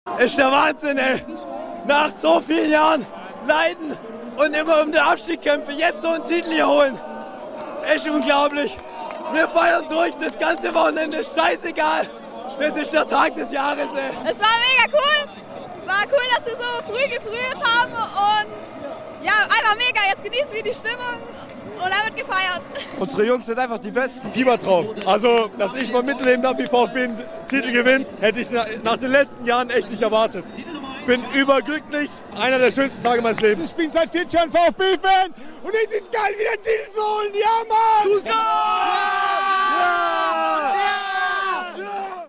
Beim Public Viewing auf dem Stuttgarter Schlossplatz waren 35.000 VfB-Anhänger völlig in Extase:
O-Ton-Collage der VfB-Fans nach dem Sieg im DFB-Pokalfinale